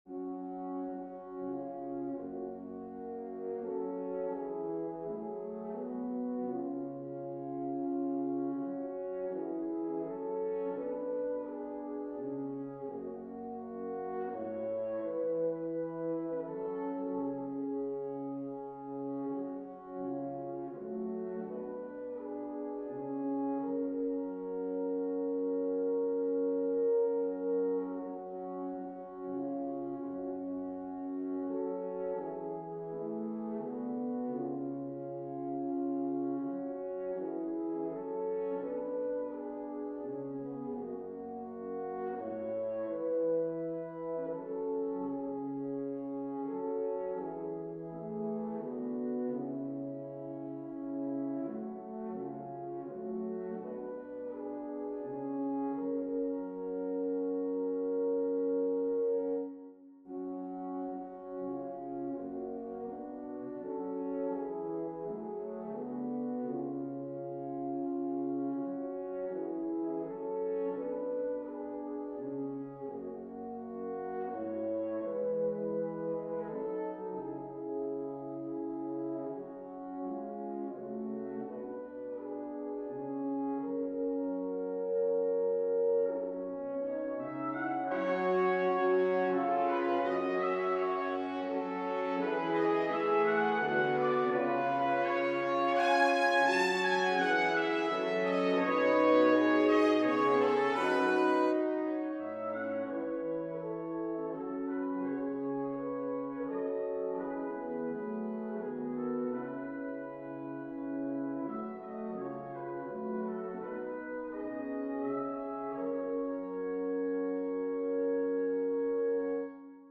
Here is my submission for this year's Christmas event, a through composed setting of the coventry carol.